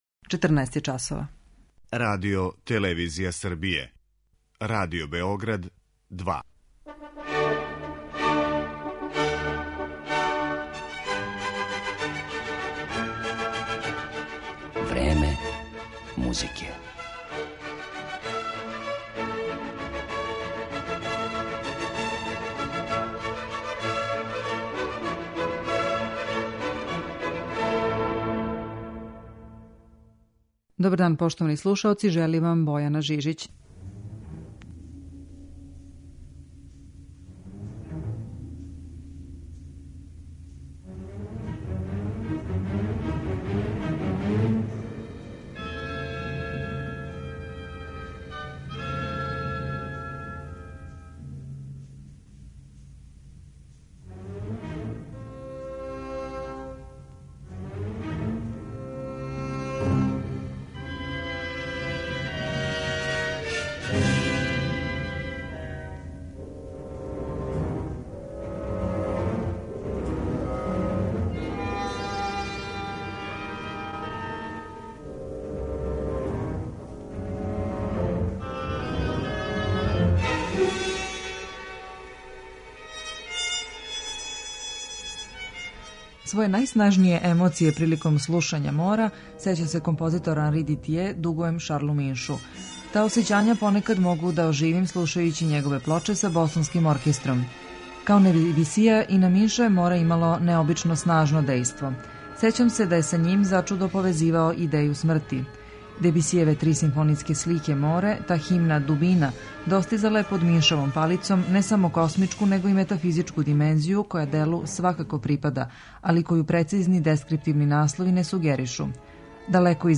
Представићемо га претежно у овом репертоару и у архивским снимцима Радио Београда.